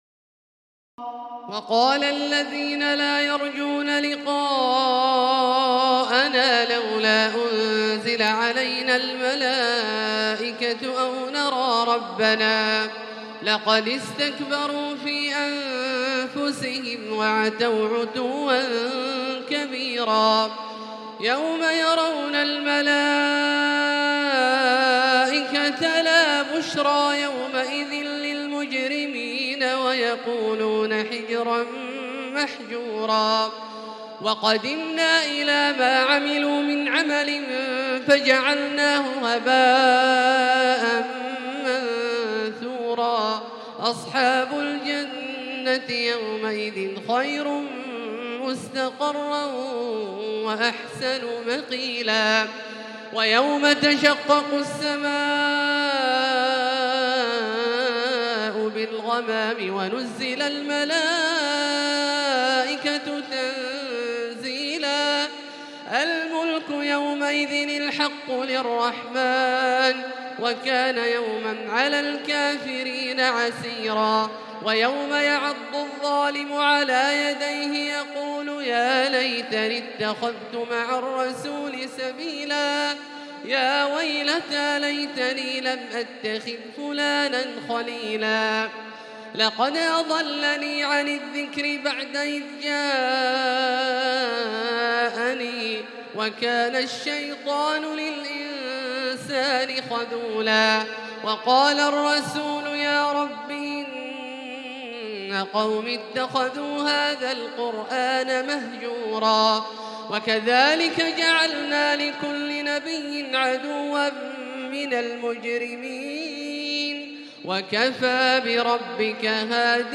تراويح الليلة الثامنة عشر رمضان 1438هـ من سورتي الفرقان (21-77) و الشعراء (1-122) Taraweeh 18 st night Ramadan 1438H from Surah Al-Furqaan and Ash-Shu'araa > تراويح الحرم المكي عام 1438 🕋 > التراويح - تلاوات الحرمين